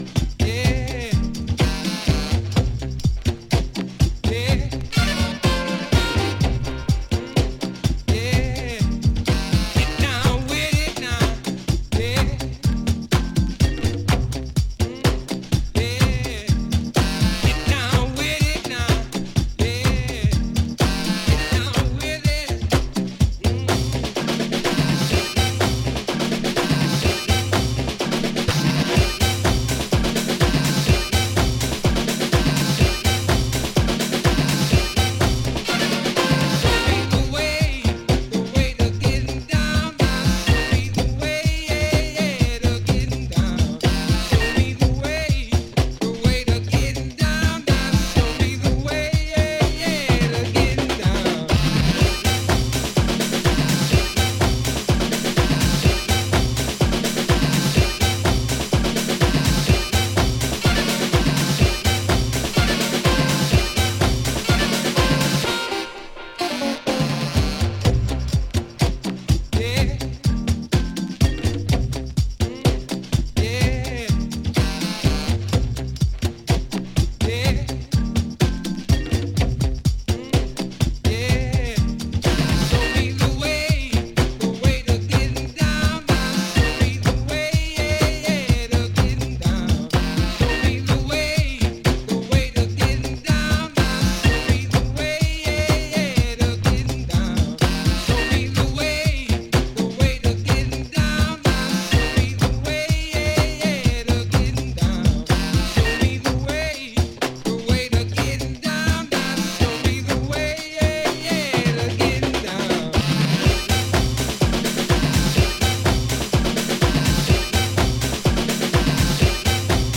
埃っぽく燻んだジャズファンク・ハウス